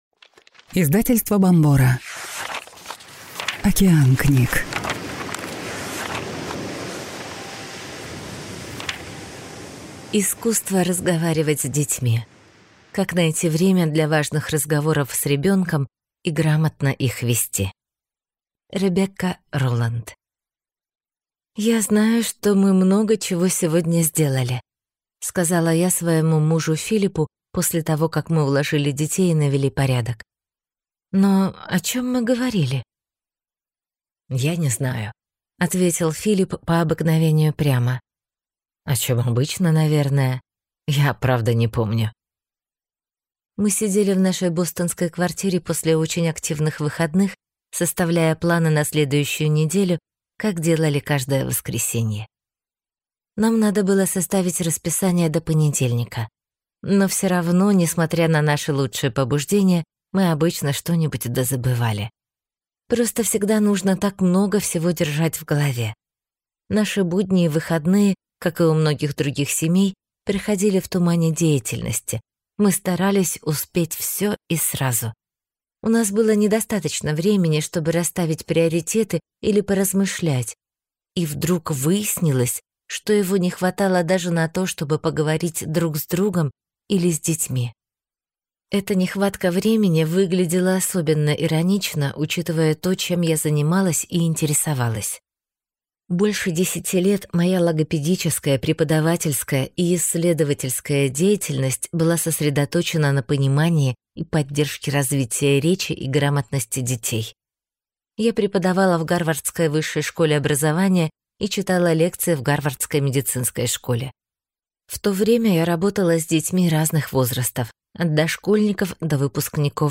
Аудиокнига Искусство разговаривать с детьми. Как найти время для важных разговоров с ребенком и грамотно их вести | Библиотека аудиокниг